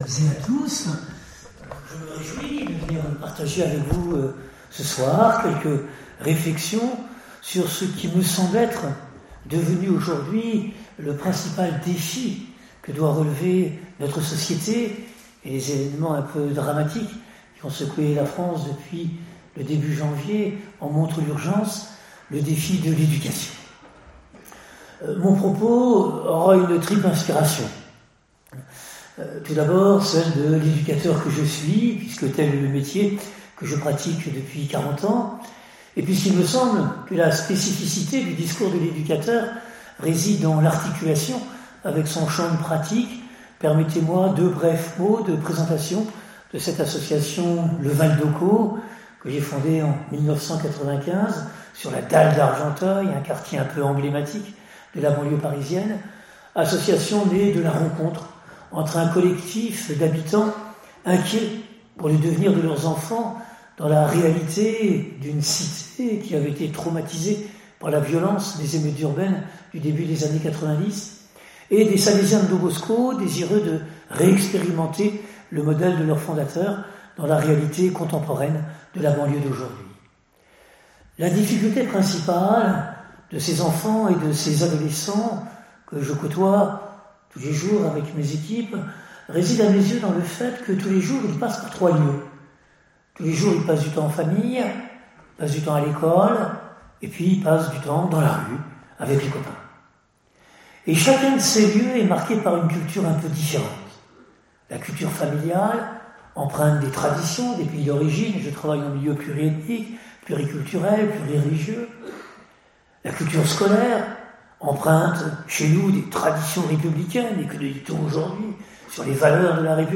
Il a fait un exposé très riche où chacun pouvait retrouver une situation qu’il avait vécue lui-même.